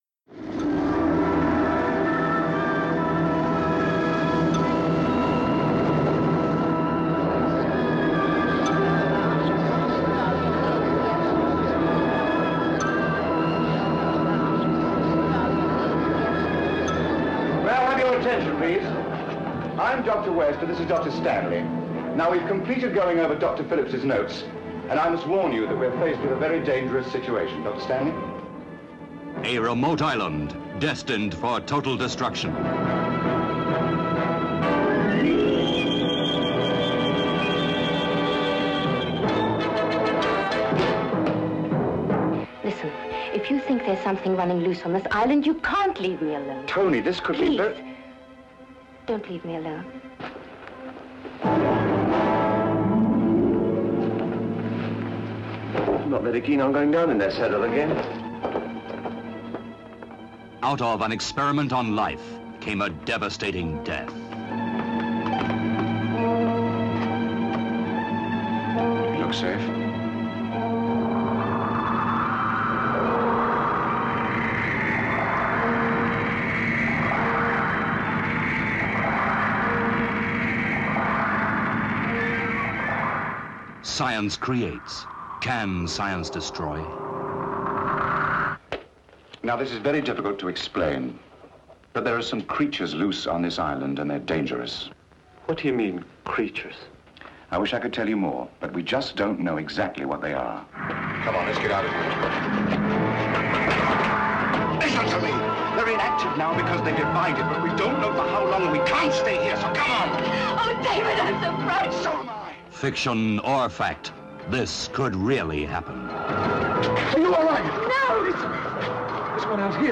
Trailer Audio: Island of Terror (1966)
island-of-terror-trailer.mp3